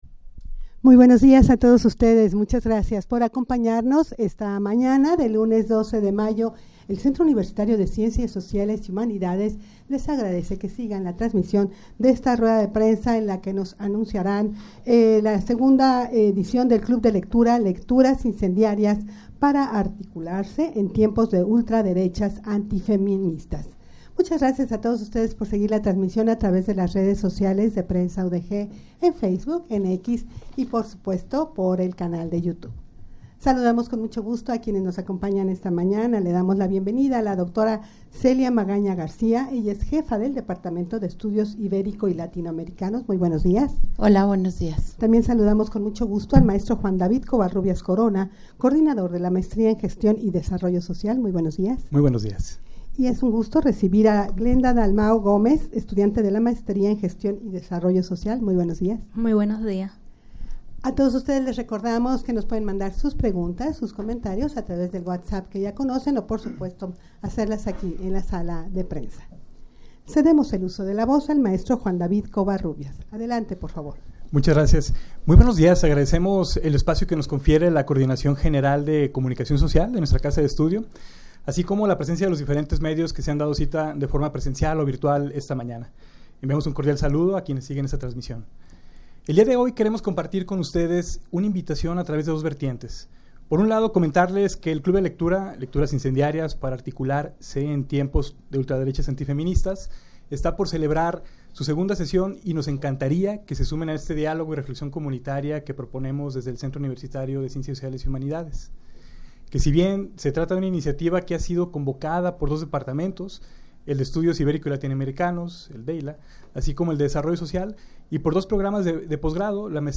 Audio de la Rueda de Prensa
rueda-de-prensa-club-de-lectura-lecturas-incendiarias-para-articular-se-en-tiempos-de-ultraderechas-antifeministas.mp3